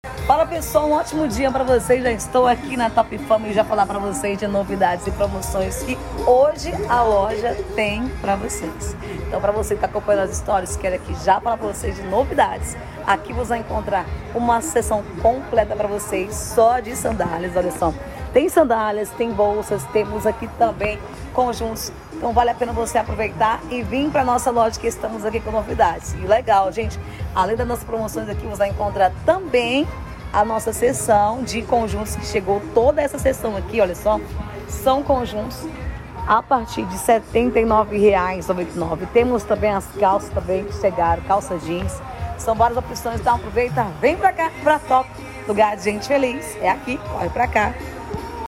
Locutora de porta de loja sound effects free download